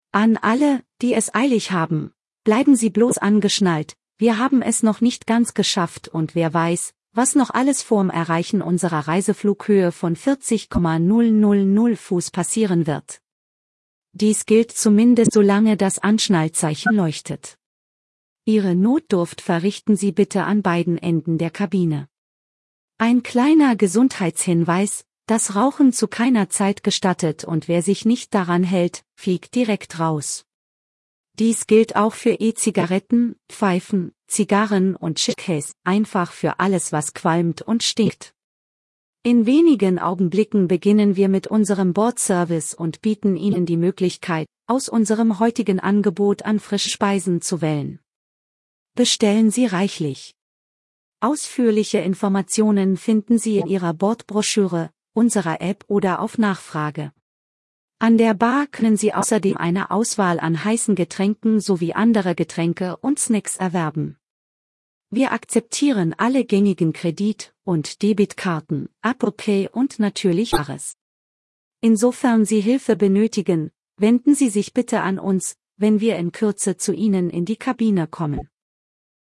AfterTakeoff.ogg